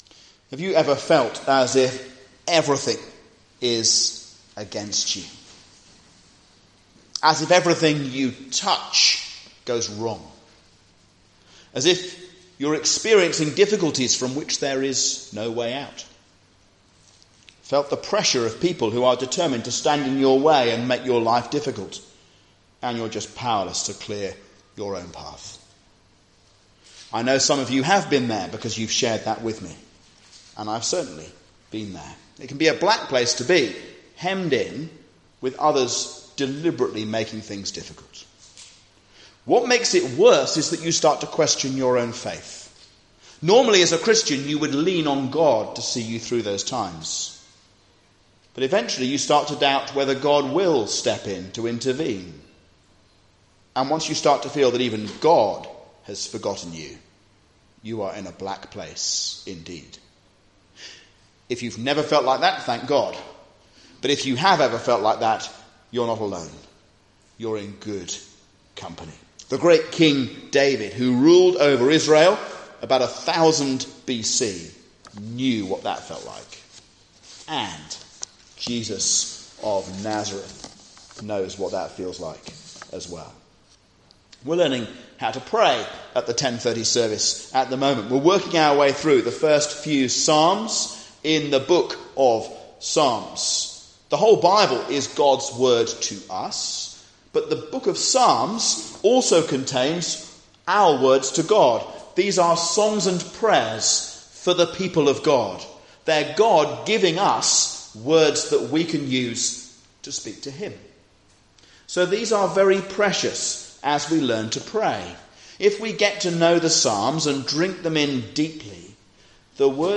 A sermon on Psalm 3